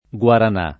vai all'elenco alfabetico delle voci ingrandisci il carattere 100% rimpicciolisci il carattere stampa invia tramite posta elettronica codividi su Facebook guarana [ gU ar # na ] o guaranà [ gU aran #+ ] s. f. (bot.)